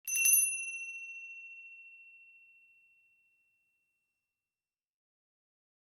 Le son que j’utilise (cloche tibétaine) si vous voulez l’utiliser :
bruit-de-clochette.mp3